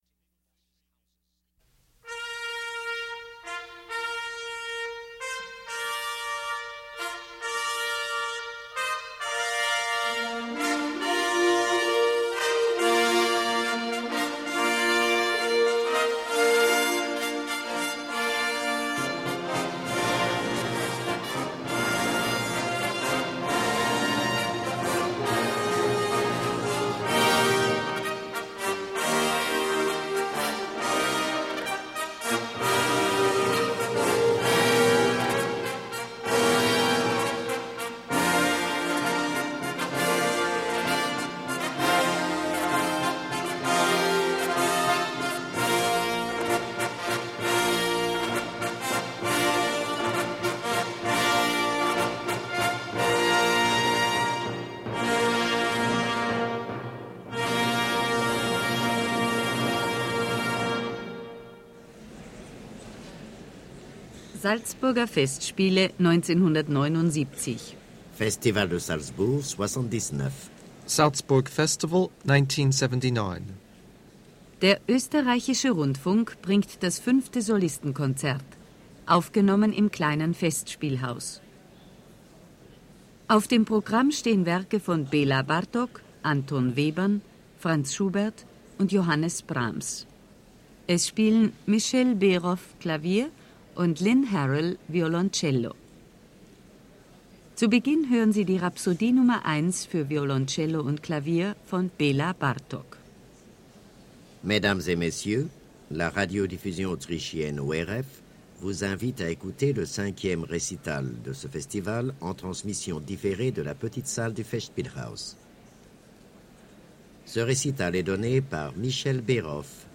Historic recitals this week. From the 1979 Salzburg Festival, a recital by Michel Beroff and Lynn Harrell in music by Bartok, Webern, Schubert and Brahms.
Lynn Harrell – Universally acknowledged as one of the world’s finest cellists.
Beginning the concert with Bartok’s Rhapsody No. 1 for cello and Piano.
The French pianist and conductor, Michel Béroff, was trained at the Nancy Conservatory, winning the 1st prize in 1962 and the prize of excellence in 1963.